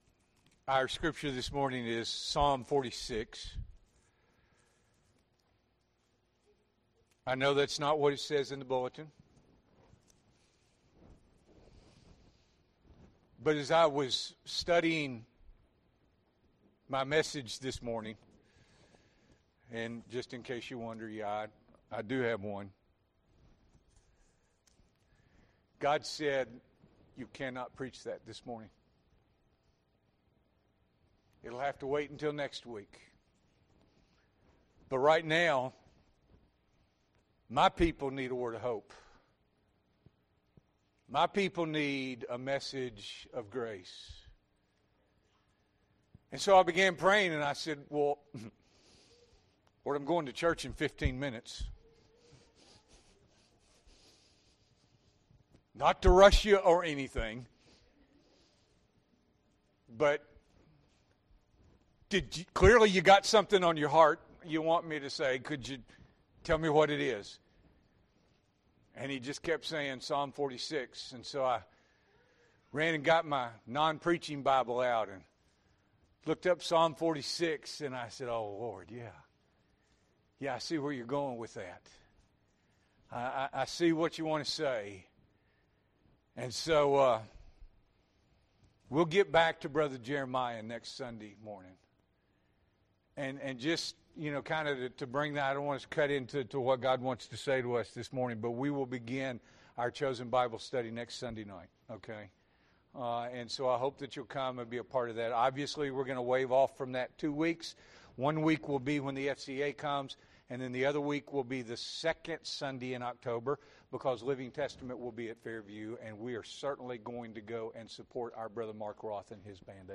September 29, 2024 – Morning Worship